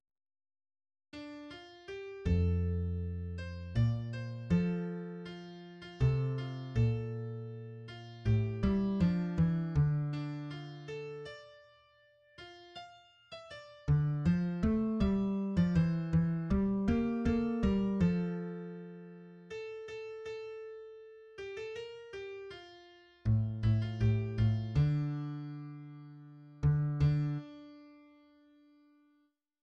3rd verse